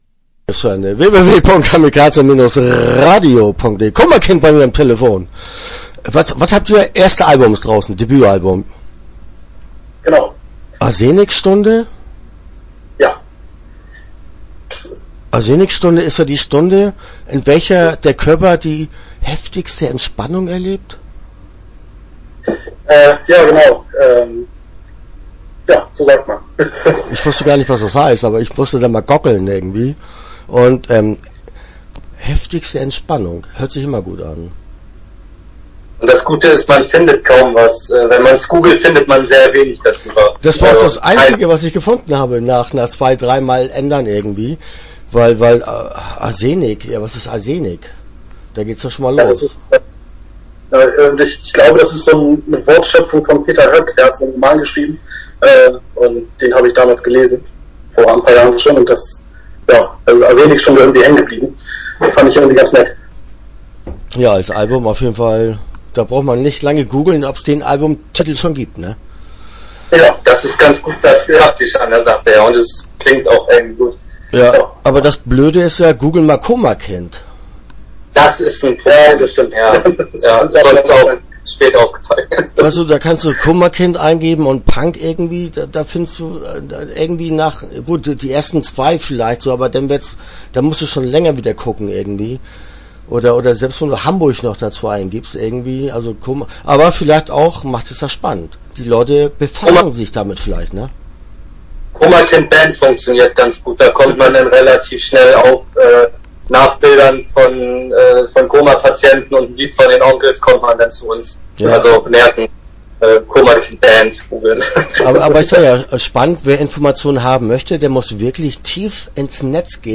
Koma Kind - Interview Teil 1 (7:57)